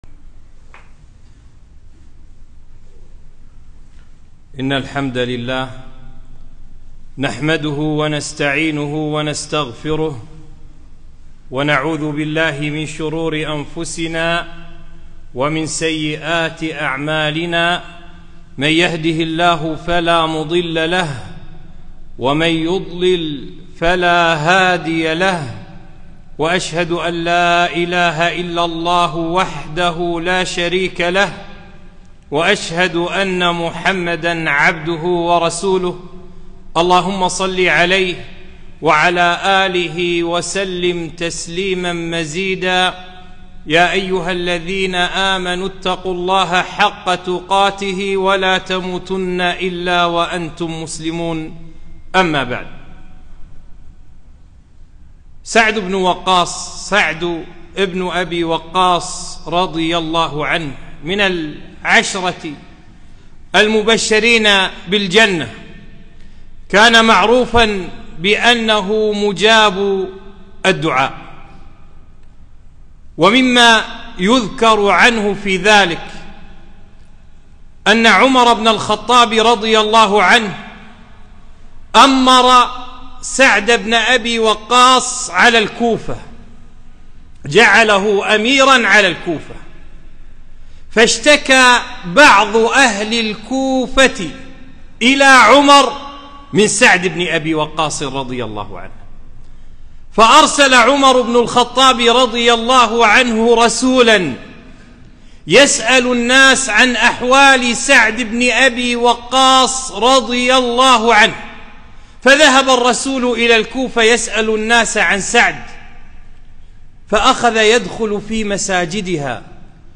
خطبة - موانع إجابة الدعاء